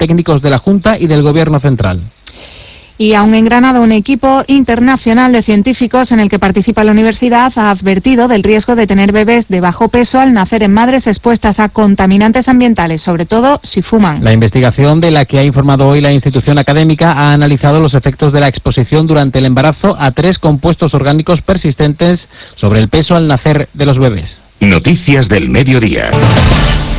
AUDIO: Onda Cero Andalucía – NOTICIAS MEDIODIA ANDALUCIA: Riesgo de tener bebés de bajo peso al nacer en madres expuestas a contaminantes ambientales